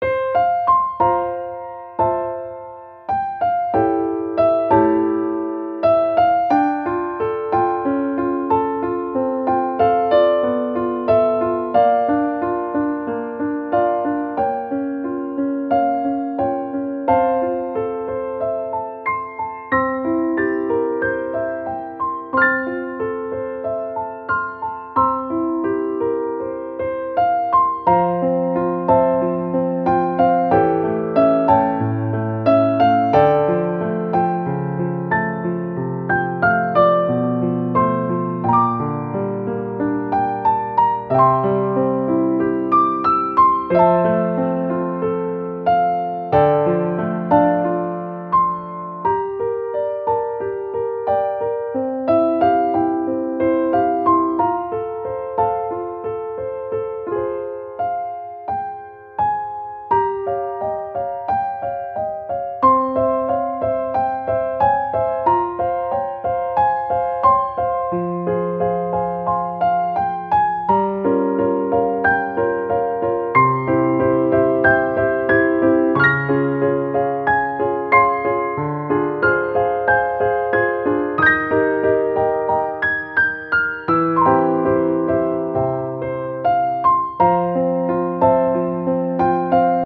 -oggをループ化-   しっとり 切ない 2:06 mp3